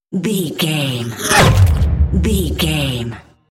Sci fi whoosh to hit
Sound Effects
futuristic
tension
woosh to hit